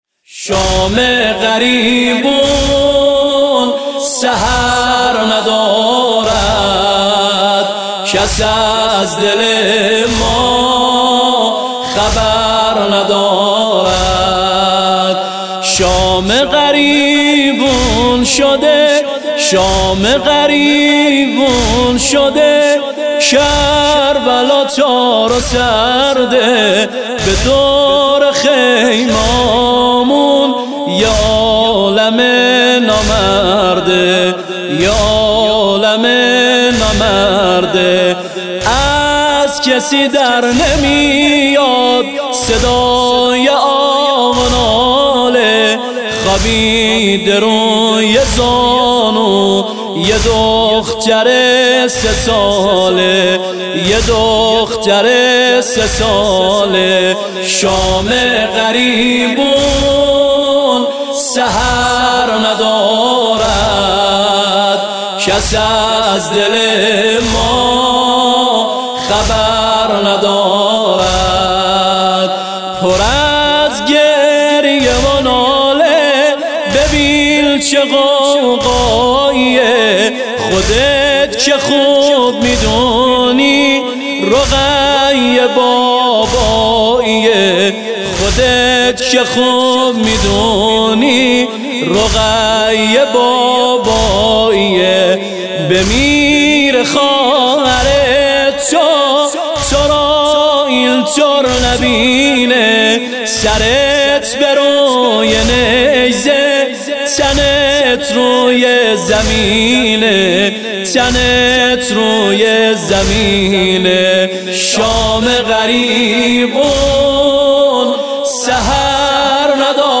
نوحه_زنجیر زنی